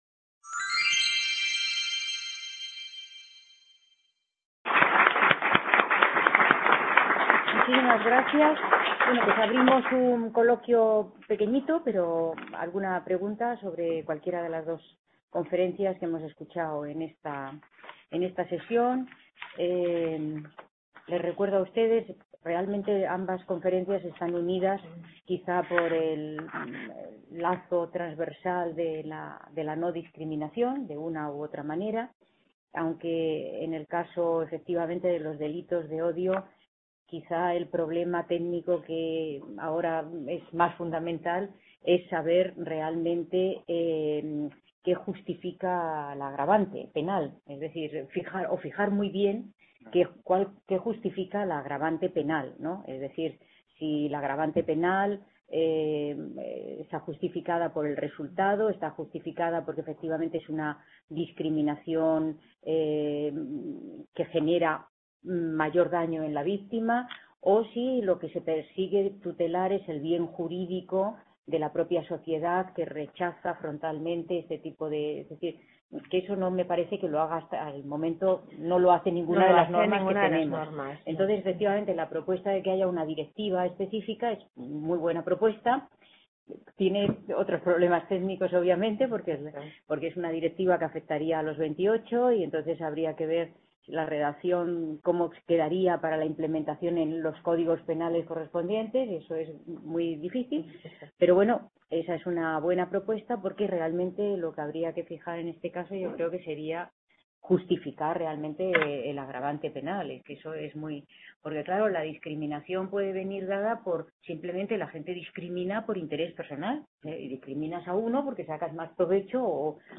C.A. Bruselas - IV Congreso Internacional sobre Unión Europea: nuevos problemas, nuevas soluciones.
Video Clase